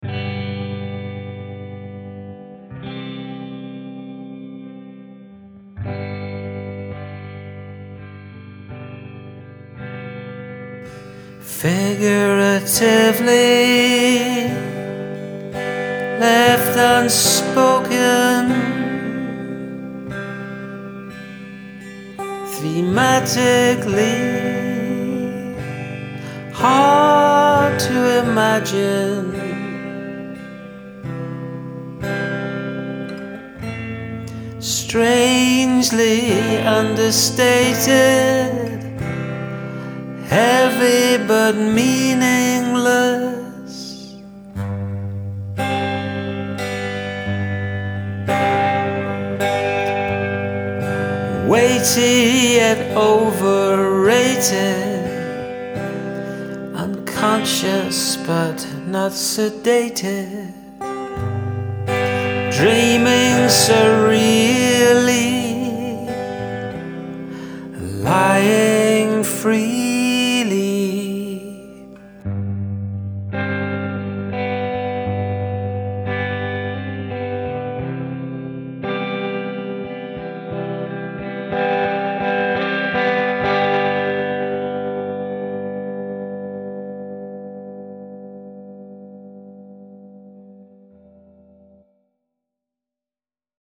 Nice meditative piece.
This one is very subtly moody.
Lovely, lifting melody, too.
Nice skirmish, this is quite meditative - and I love your guitar sound
Nice tremolo guitar track and vocals 😃